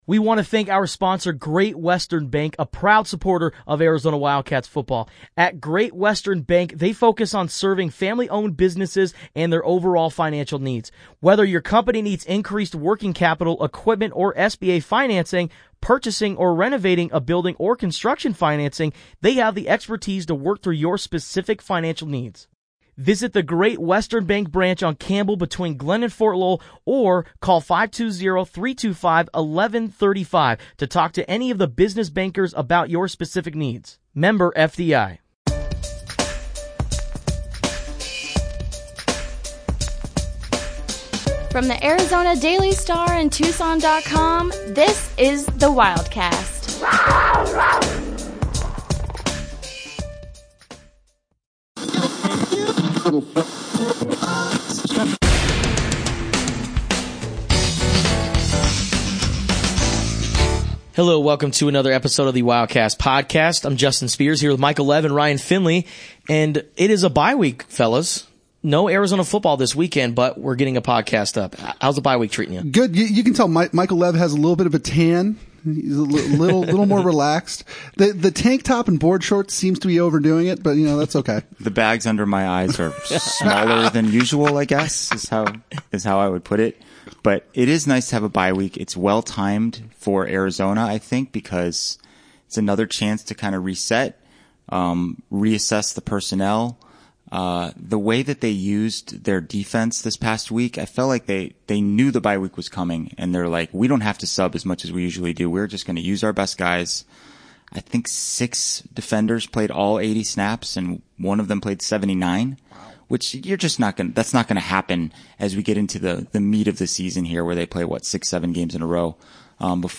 getting back into the studio for another podcast.